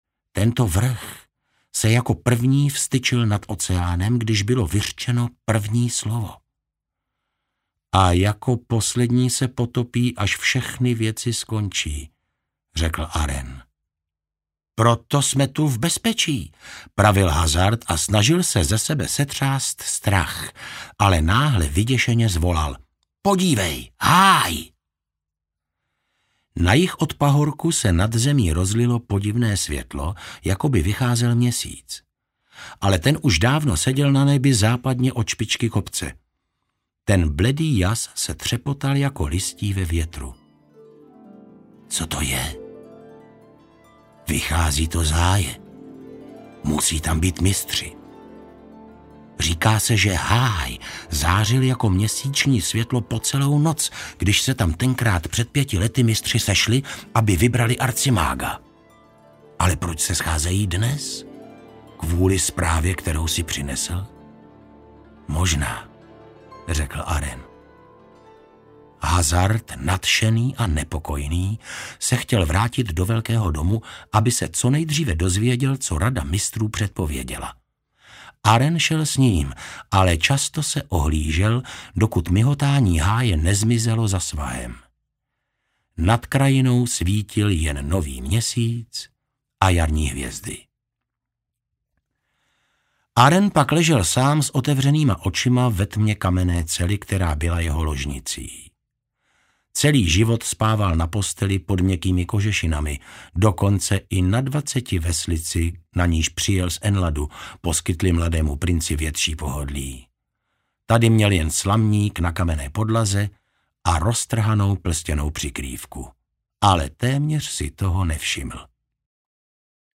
Nejvzdálenější pobřeží audiokniha
Ukázka z knihy